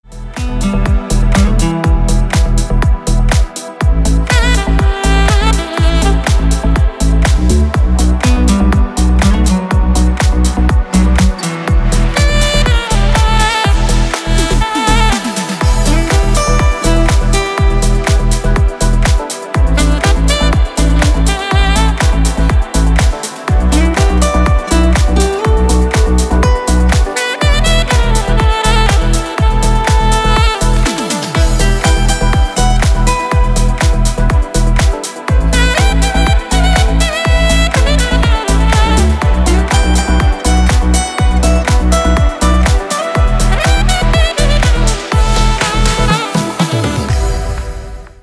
• Качество: Хорошее
• Категория: Красивые мелодии и рингтоны